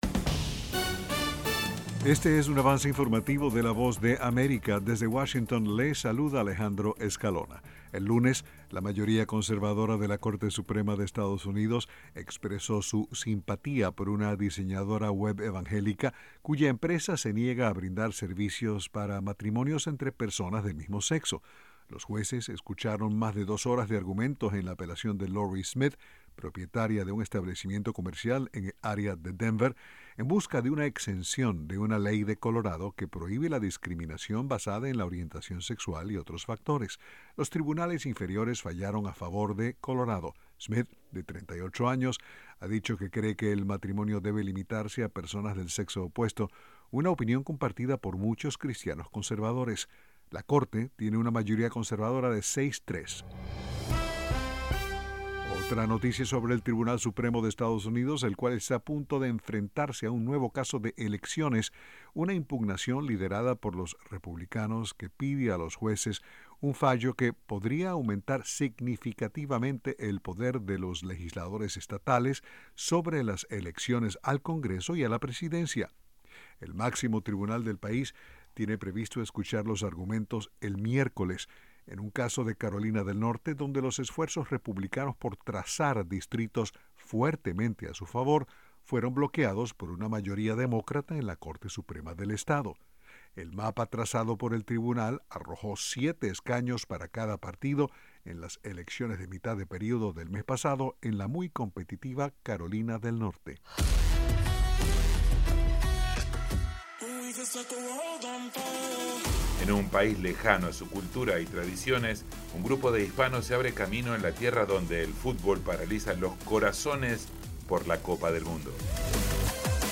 Este es un avance informativo presentado por la Voz de América en Washington.